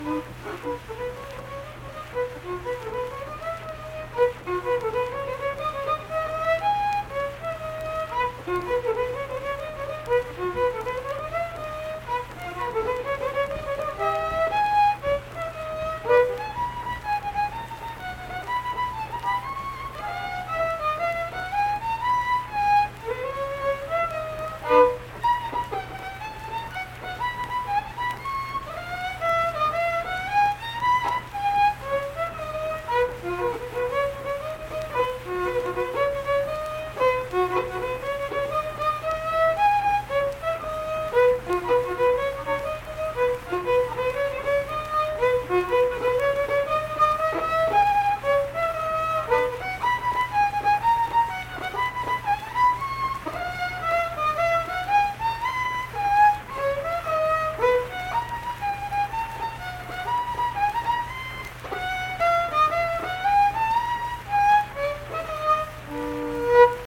Unaccompanied vocal and fiddle music
Instrumental Music
Fiddle
Pleasants County (W. Va.), Saint Marys (W. Va.)